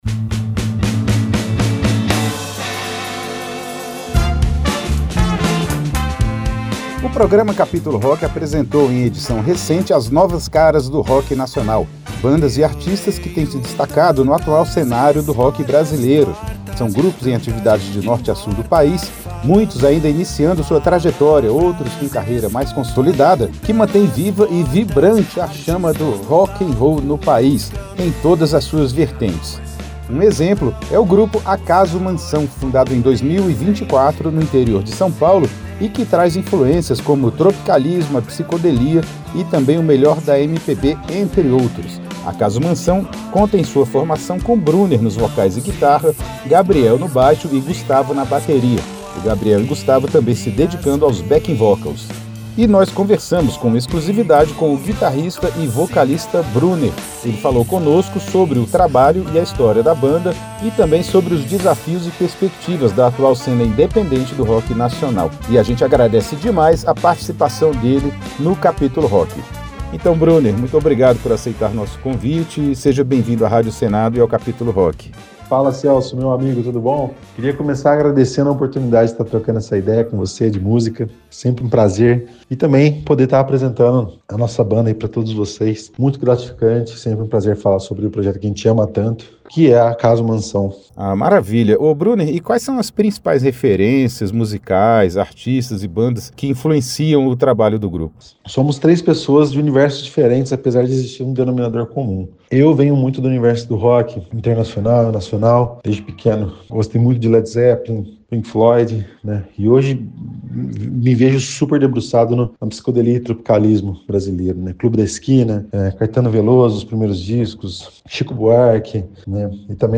Uma viagem musical pelo melhor do rock'n roll